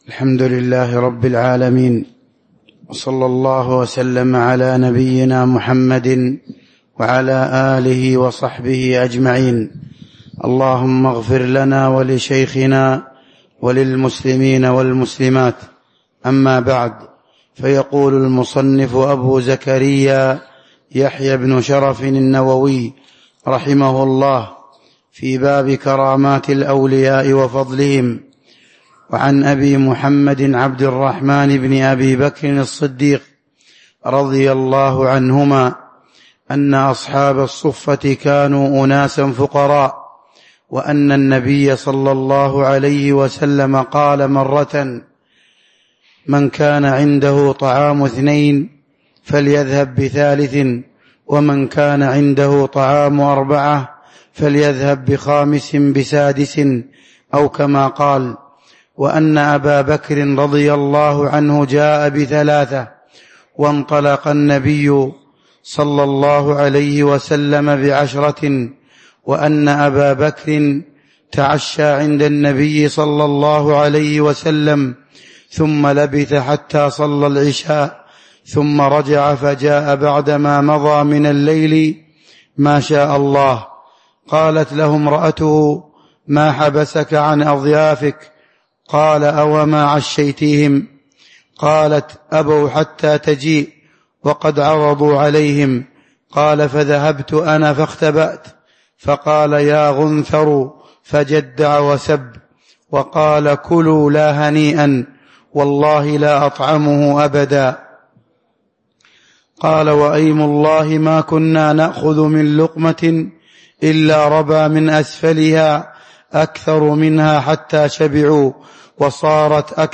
تاريخ النشر ١٢ رمضان ١٤٤٥ هـ المكان: المسجد النبوي الشيخ